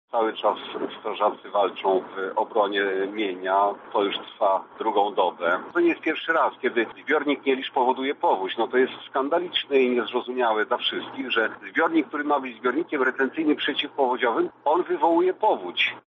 Jak mówi Andrzej Jakubiec, burmistrz Krasnegostawu, przyczyną gwałtownego przyboru wody w Wieprzu było spuszczanie wód ze zbiornika w Nieliszu.